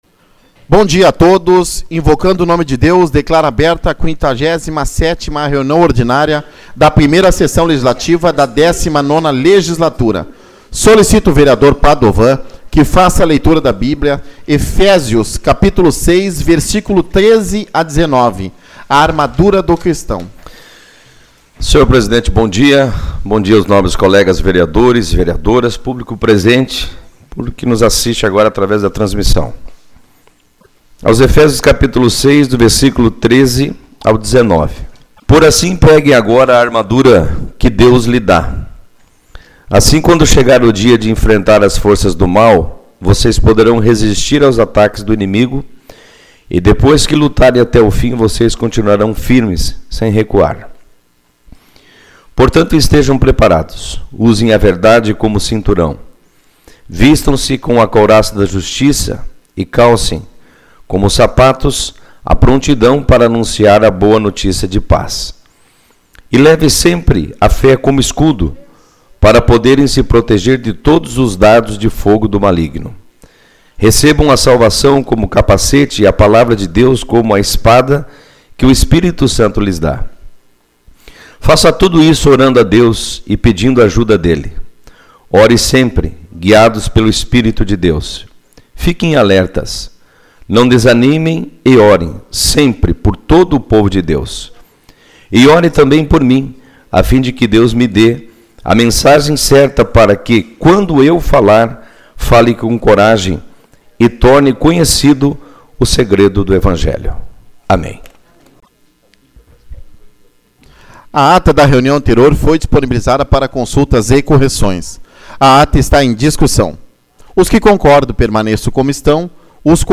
11/09 - Reunião Ordinária